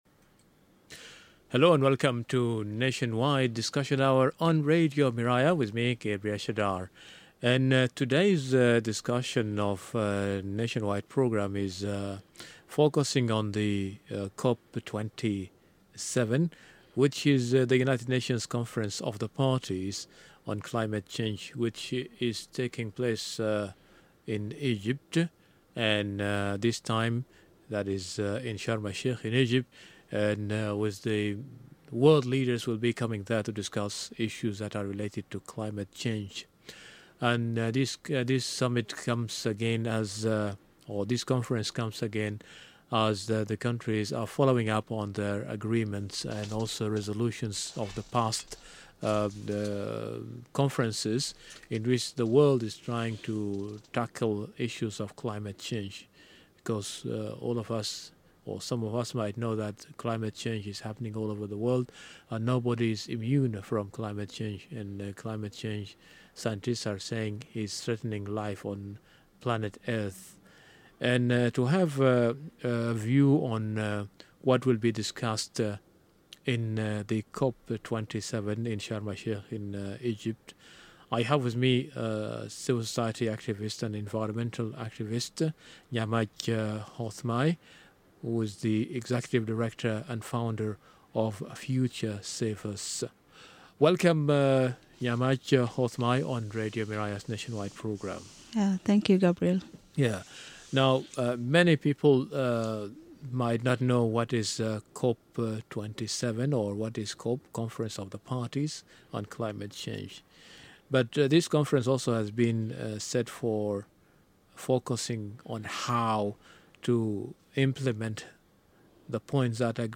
Nationwide Discussion on Climate Change